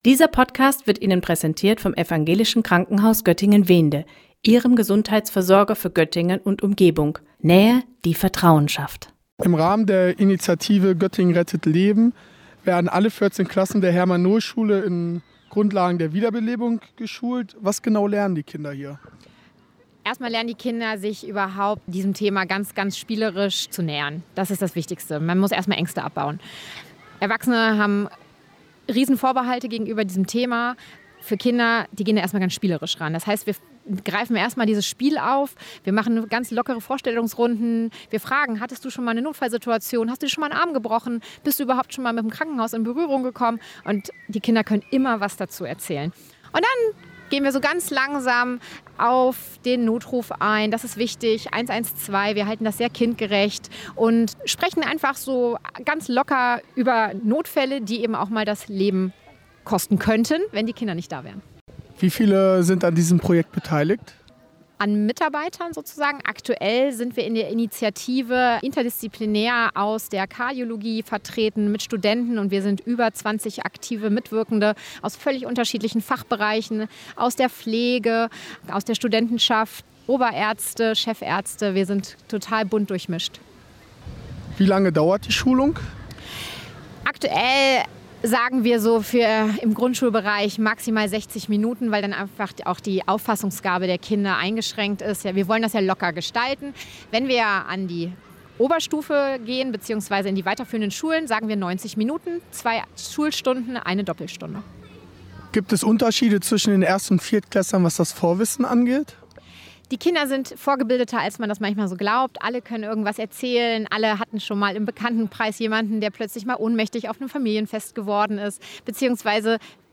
Aus diesem Grund waren Vertreter der Initiative „Göttingen rettet Leben“ von der UMG am Montag in der Herman-Nohl-Schule, die bald in Grundschule Südstadt umbenannt wird.